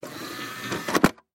Звуки дисковода